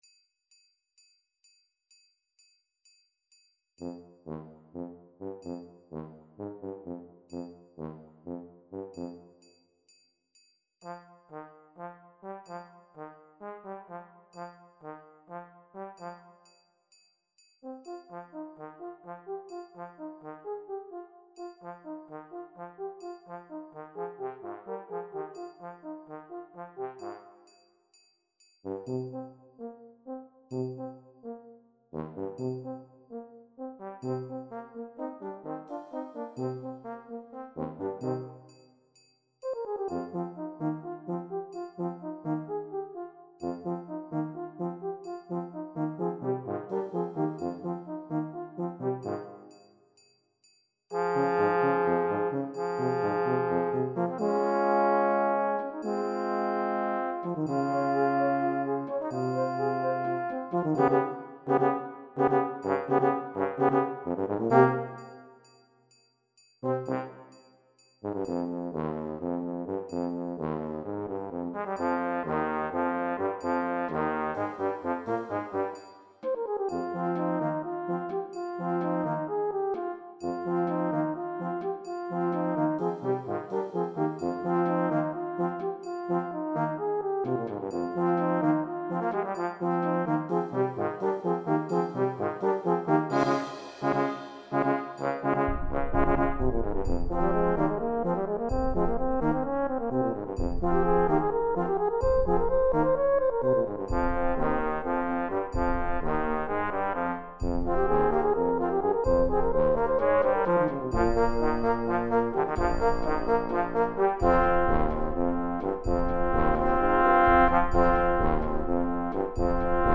where the metronome is at 128 instead of 138.